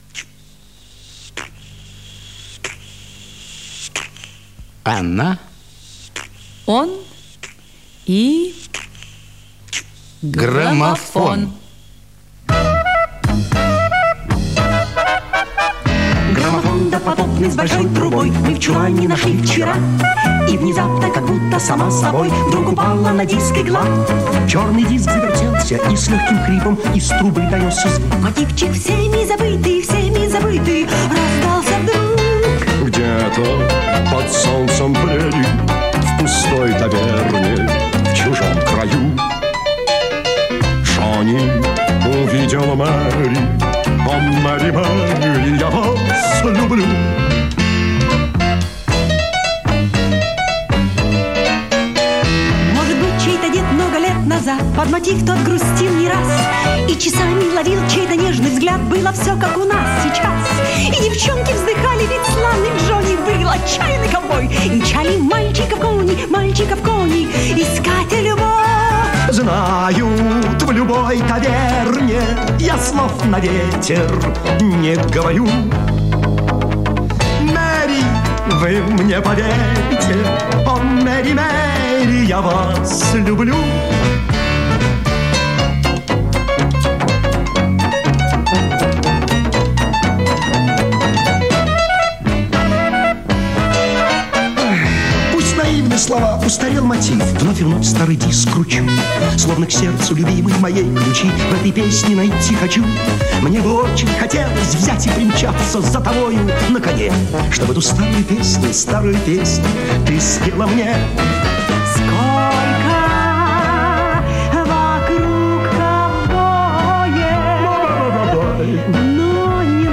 Радиопередача Она, он и граммофон. Ведущие - Л.Голубкина и А.Миронов
radioperedacha-ona,-on-i-grammofon.-veduschie---l.golubkina-i-a.mironov.mp3